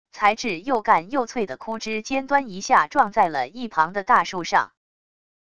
材质又干又脆的枯枝尖端一下撞在了一旁的大树上wav音频